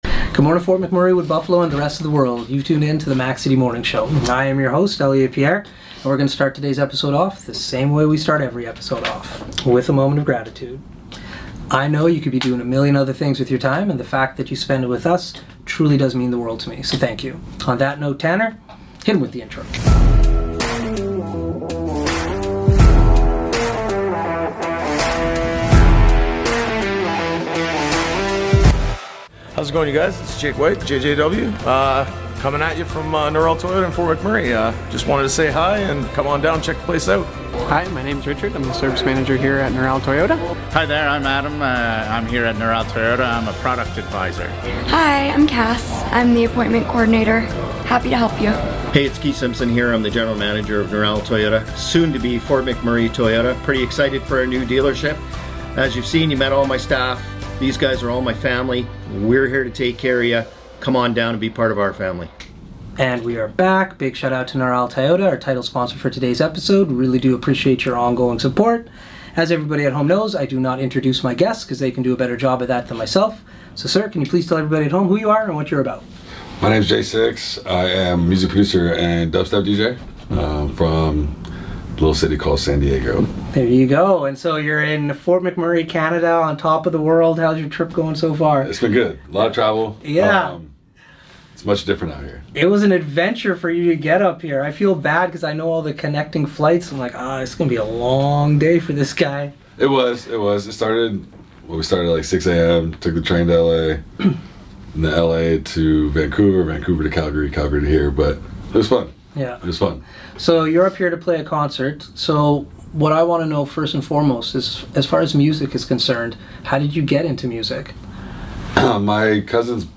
a Dub Step DJ!